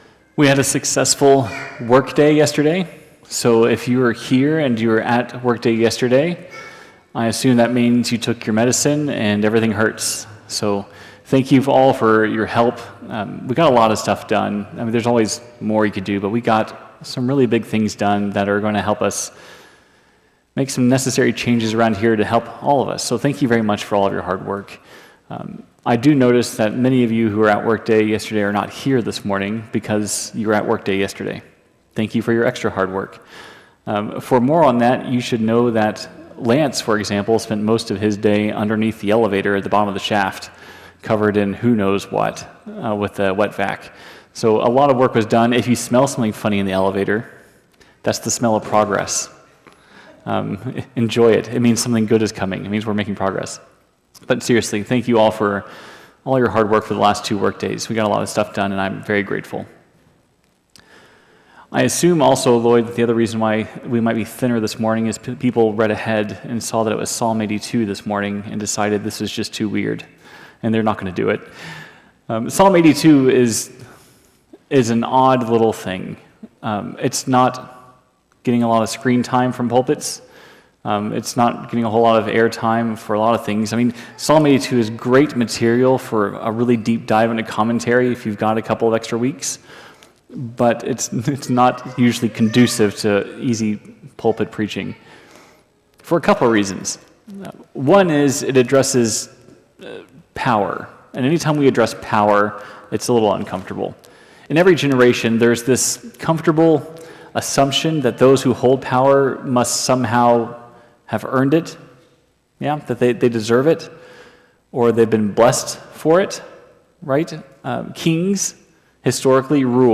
The sermon pushes the church to reflect on its own role in upholding justice and living faithfully.